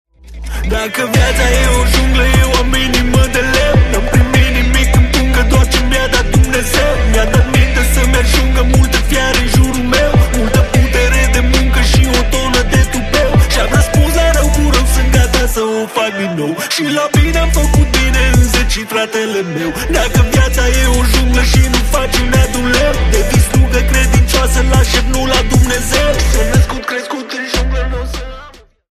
Romaneasca